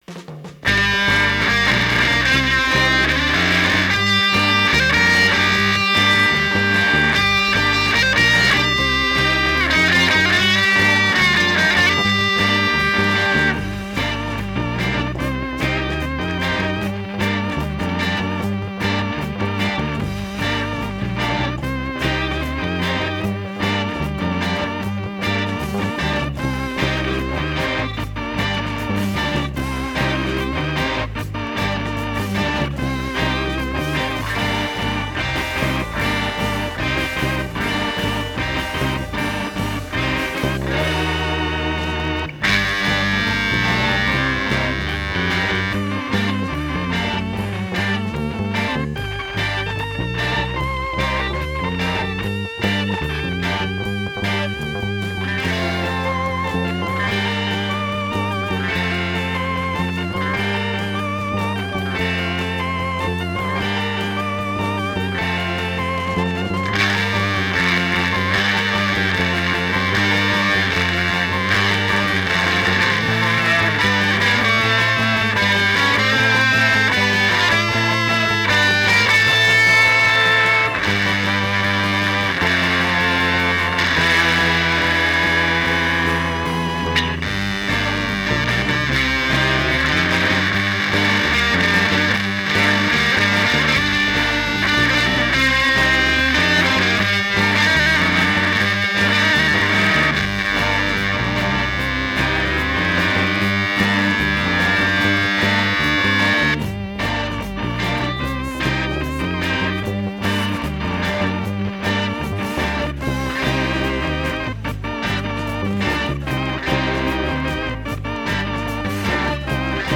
Influenciados pelo rock 60's, garage punk e psicodelia